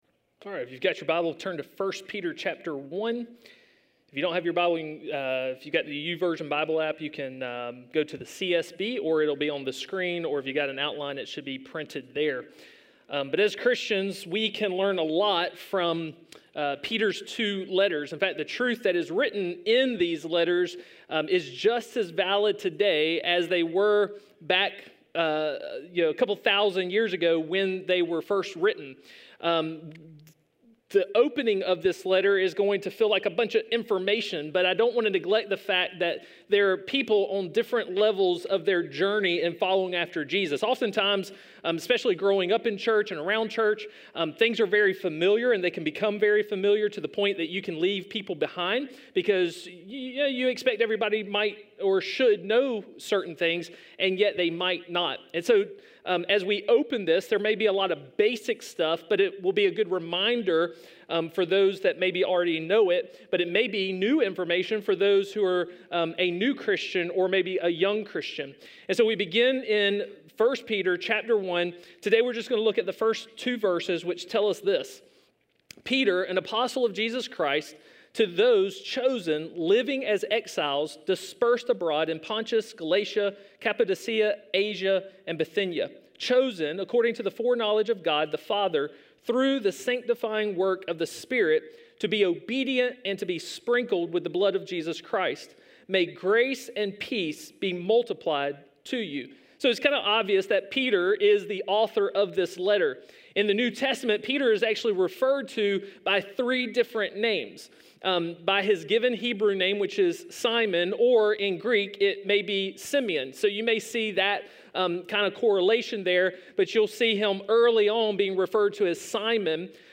A message from the series "Love Awakened."